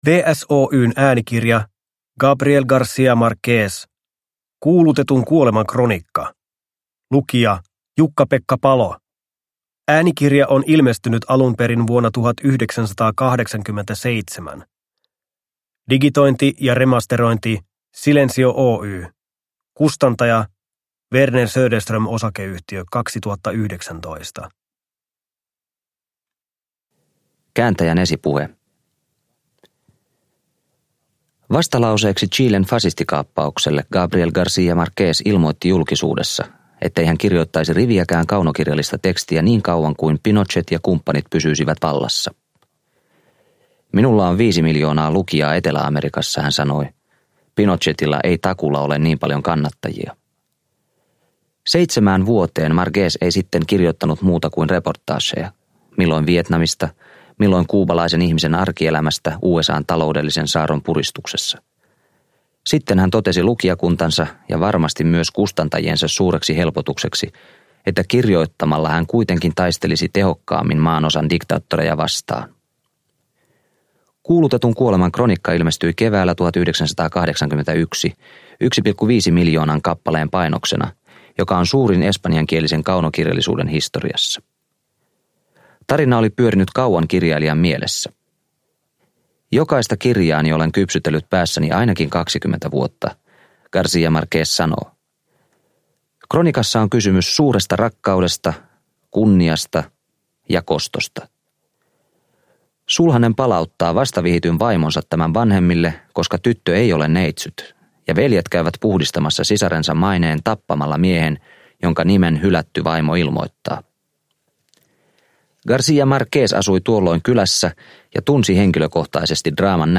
Uppläsare: Jukka-Pekka Palo